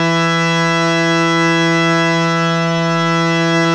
52-key08-harm-e3.wav